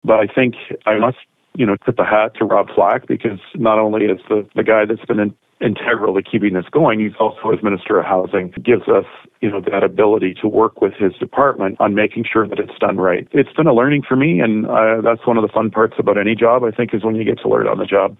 Sloan admits it will be a steep learning curve moving forward.